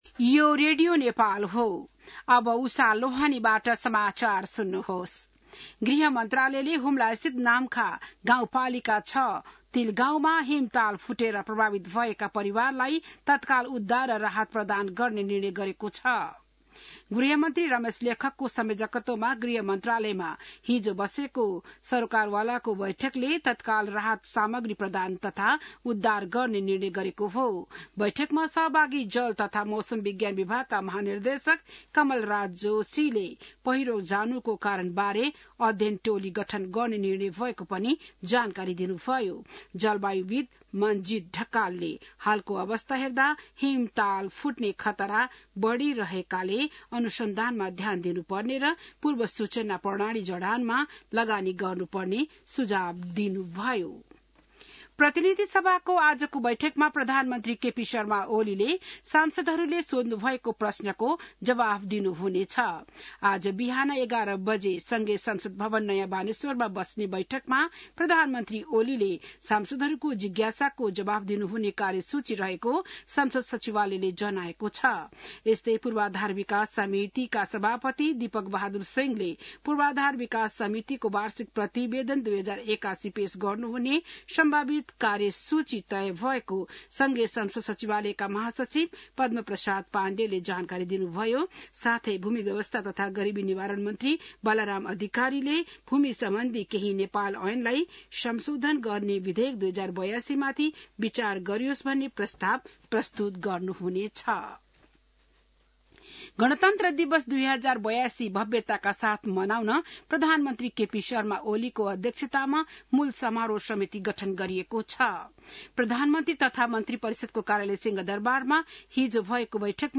बिहान १० बजेको नेपाली समाचार : ६ जेठ , २०८२